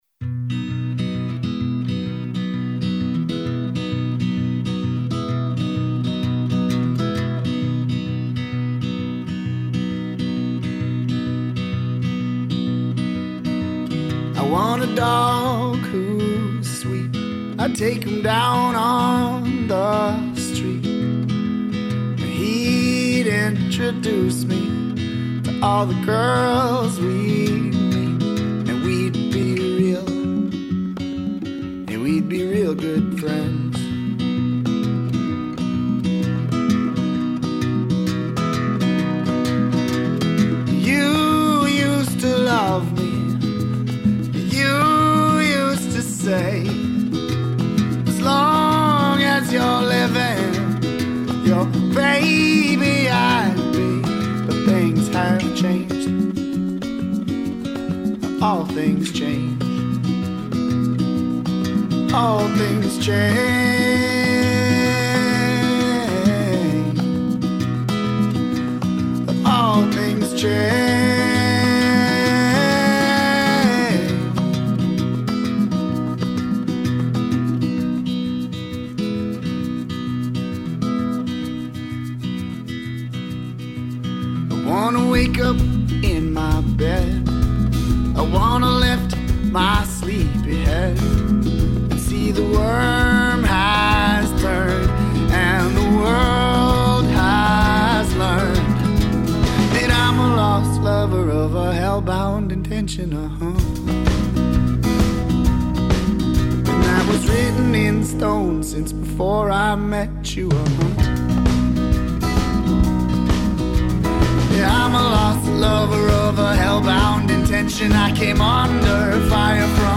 Well this is pretty.